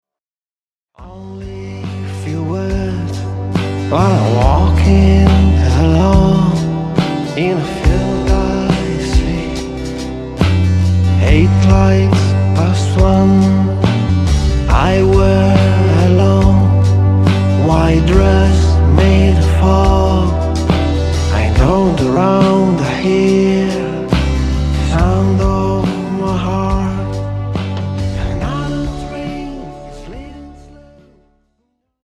Chitarre